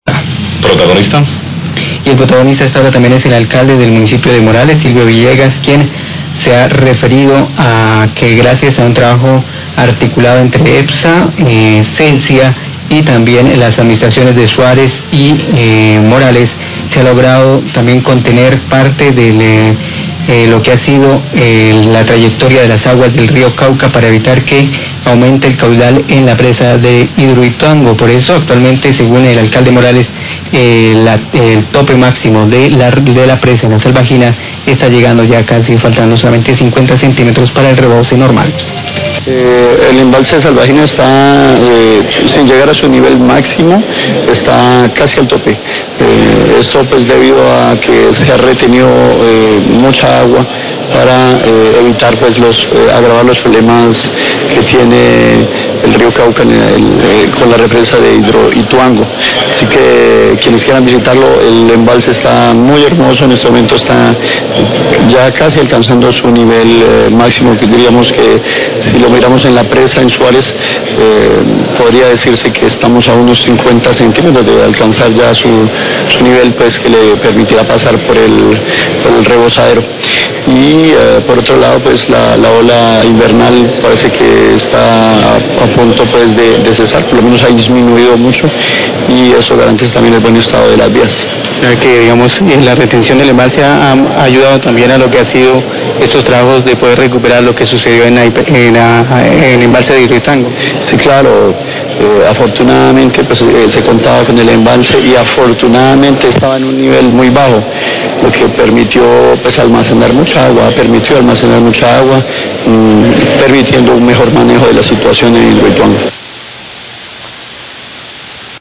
Radio
Gracias al trabajo conjunto articulado entre Epsa, Celsia, Administraciones de Suárez y Morales, se ha logrado contener parte de la trayectoria de las aguas del Río Cauca, para evitar que aumente el caudal en la represa de Hidroituango. Declaraciones de Silvio Villegas, Alcalde de Morales.